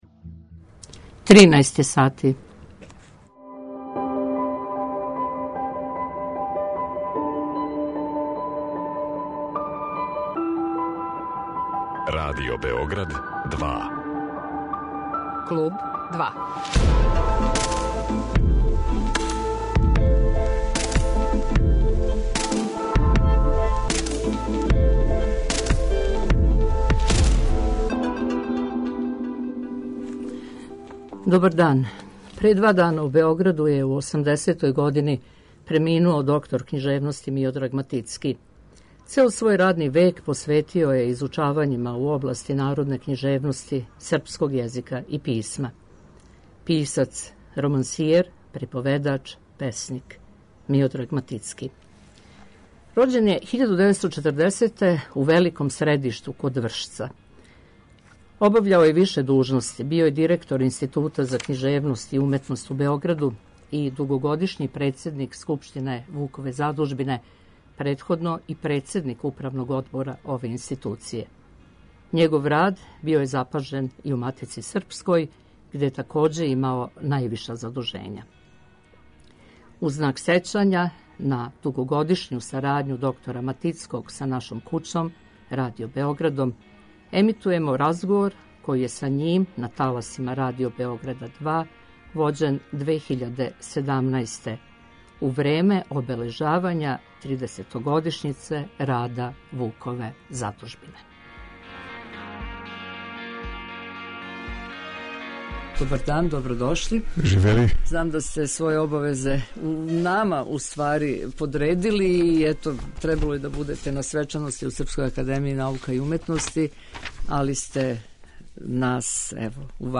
Разговор поводом обележавања тридесетогодишњице рада Вукове задужбине (емисија из 2017. године)